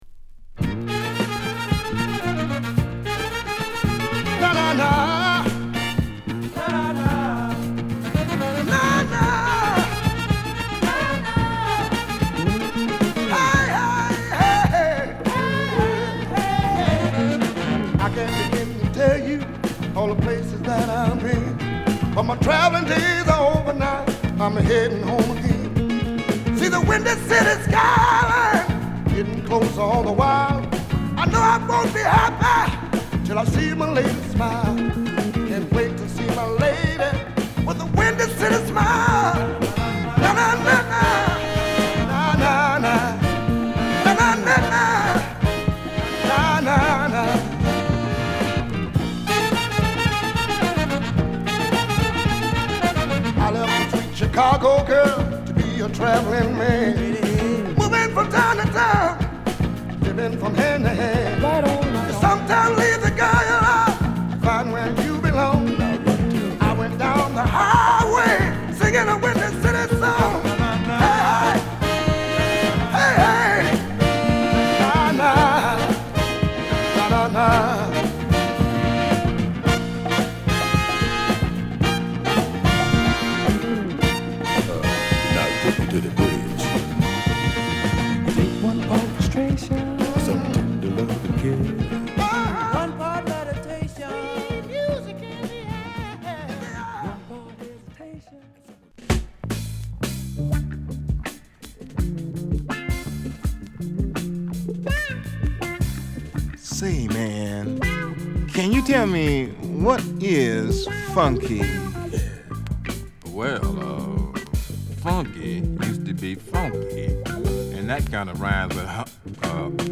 ドゥーワップグループから始まったヴォーカル・グループ